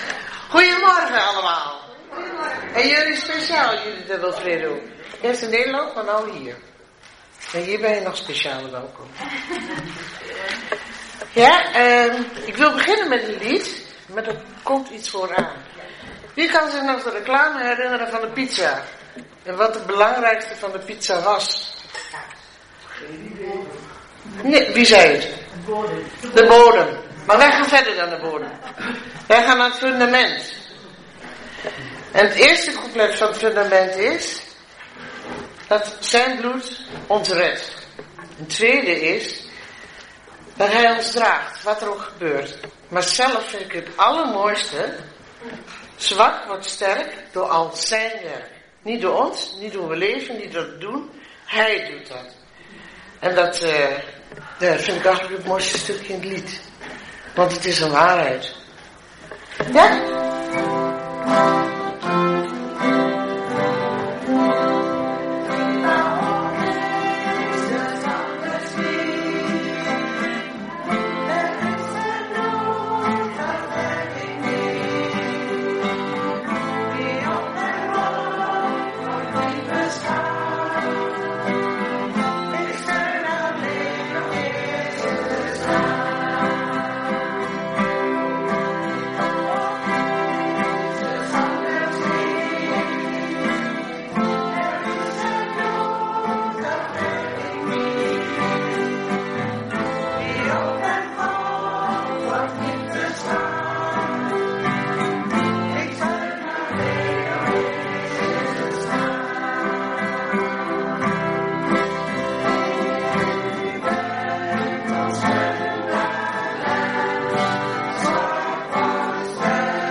1 maart 2026 dienst - Volle Evangelie Gemeente Enschede
Preek